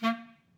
Clarinet
DCClar_stac_A#2_v3_rr2_sum.wav